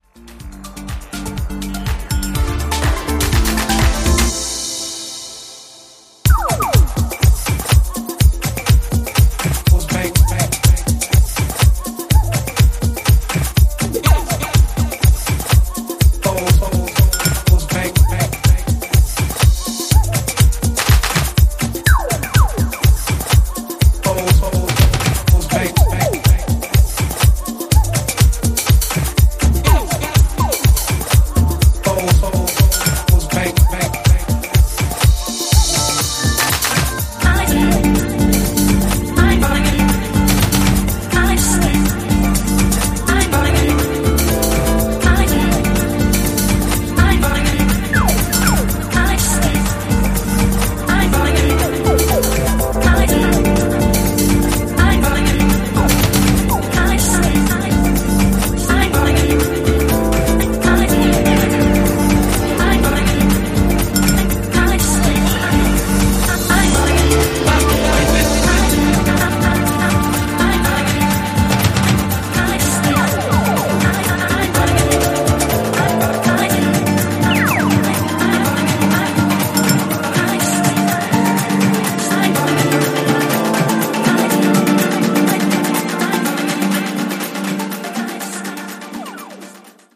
Disco / Balearic